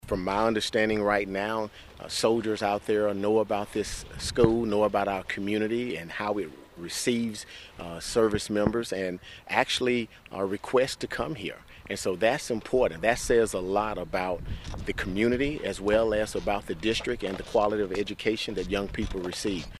Geary County Schools USD 475 hosted a ribbon cutting and dedication ceremony for the 437,000 square foot facility.